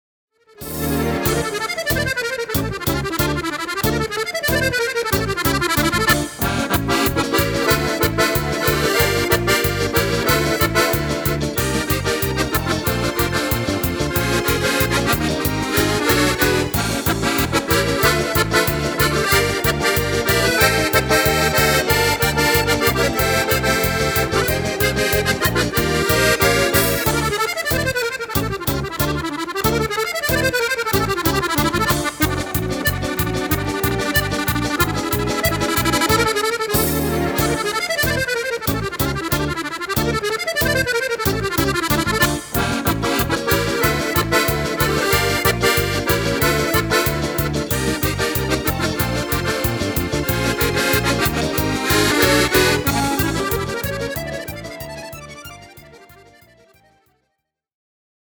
Allegro fast
Fisarmonica